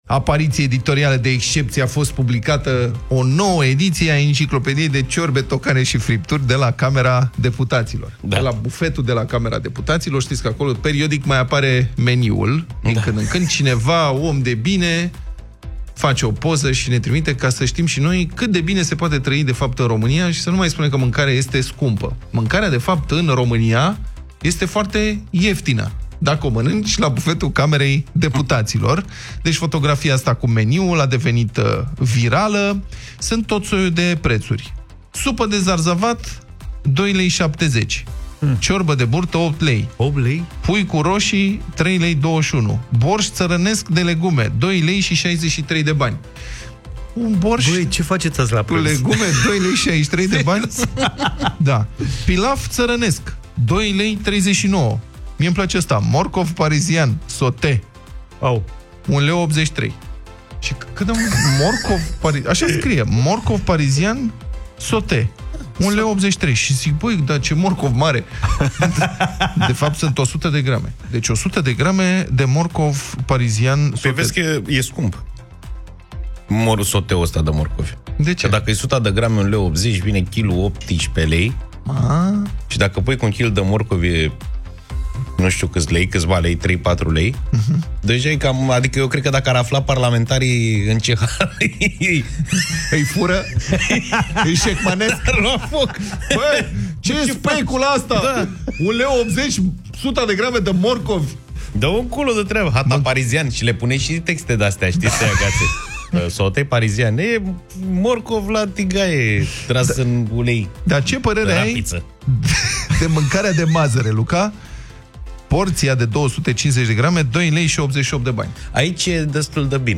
au răsfoit și ei meniul de la Parlament, în această dimineață în Deșteptarea.